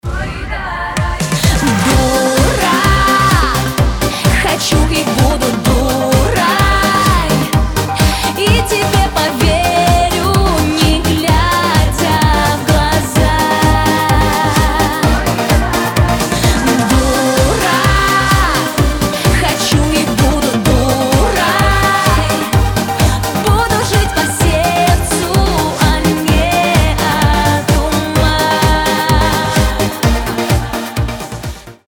• Качество: 320, Stereo
поп